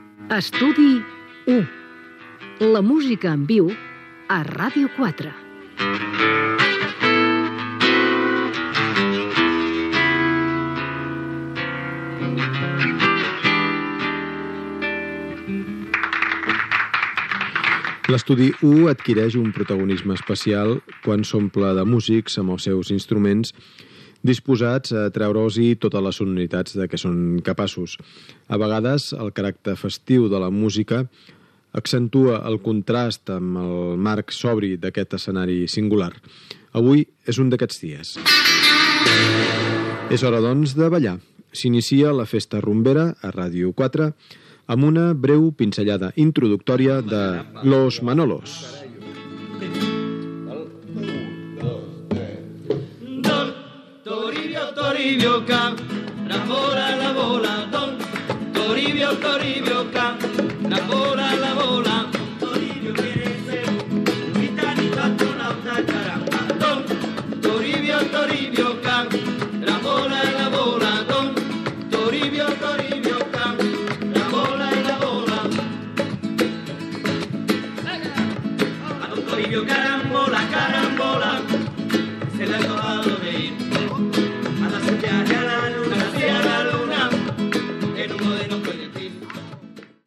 Careta del programa
espai dedicat a la rumba catalana
Musical